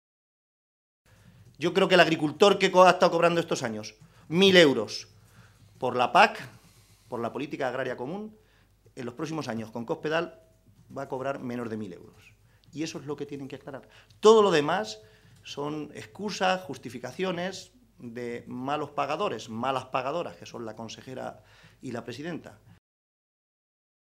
En la misma rueda de prensa, y a preguntas de los medios, el diputado regional del PSOE se ha referido a la conferencia sectorial de Agricultura que, ayer, decidía el reparto en España de los fondos de la Política Agraria Común (PAC).